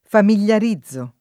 familiarizzare v.; familiarizzo [ famil L ar &zz o ]